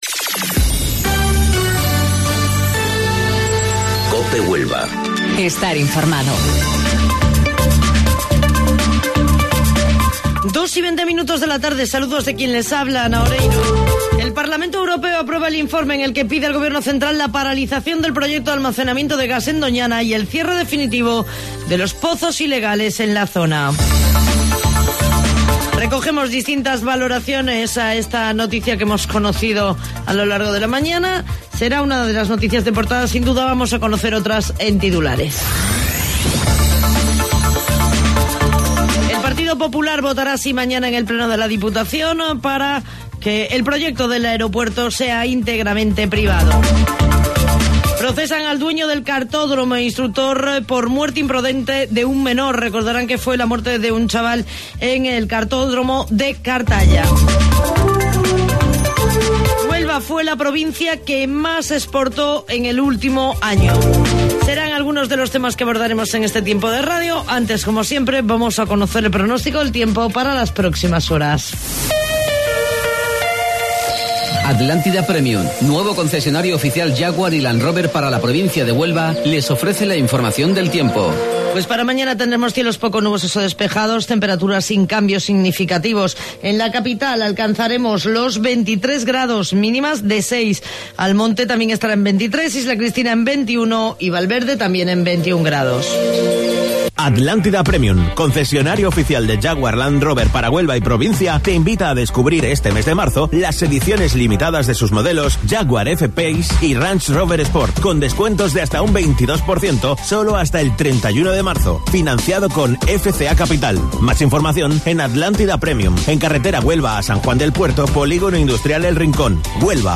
AUDIO: Informativo Local 14:20 del 21 de Marzo